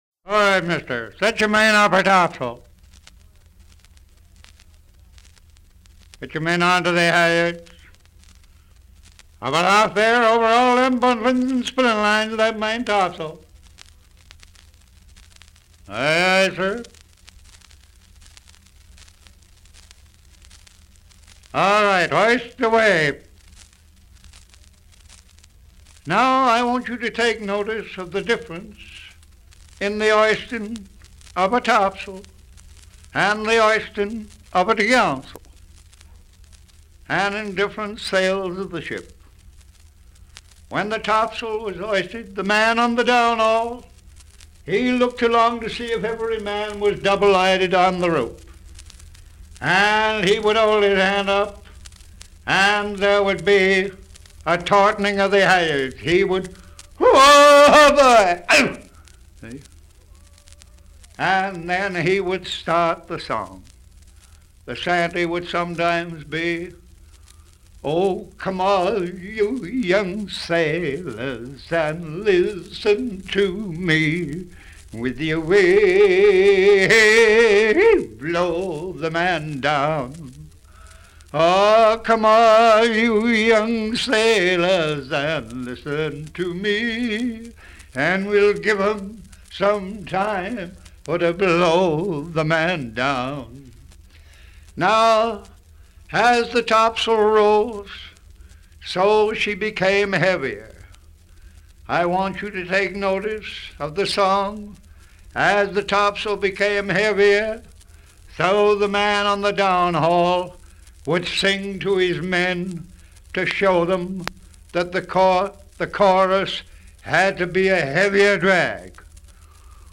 Enregistré à Sailor Snug Harbor, staten Island New-York
Pièce musicale éditée